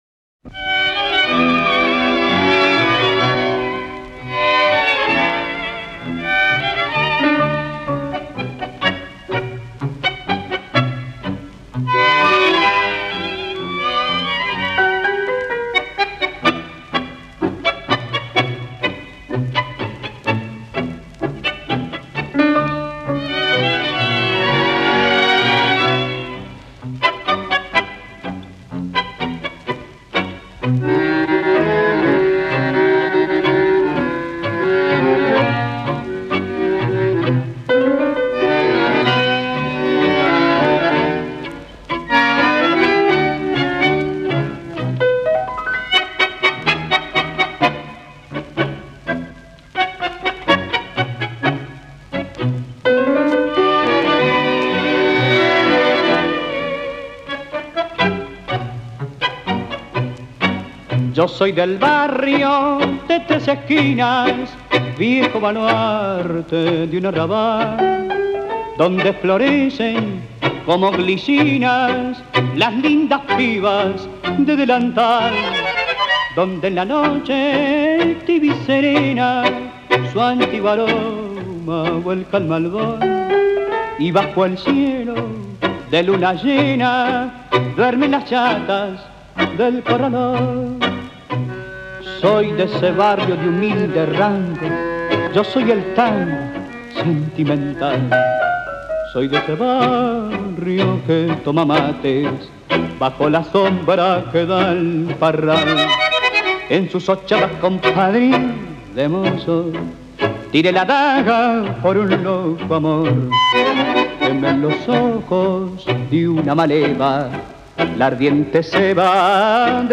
I am the sentimental tango.